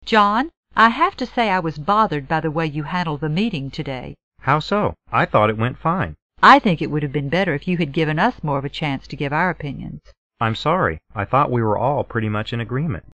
办公室英语会话第126期-Unveiling a disagreement 提出反对意见